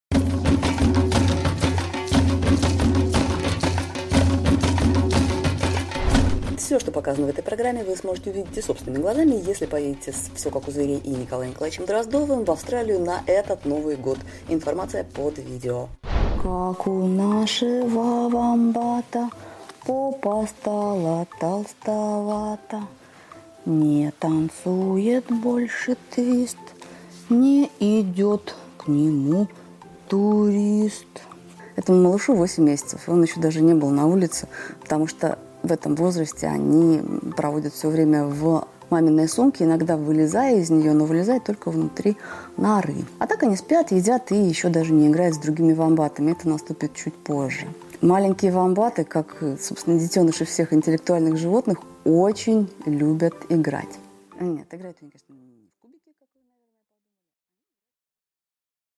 Аудиокнига Вомбат: ум, зад и кубики | Библиотека аудиокниг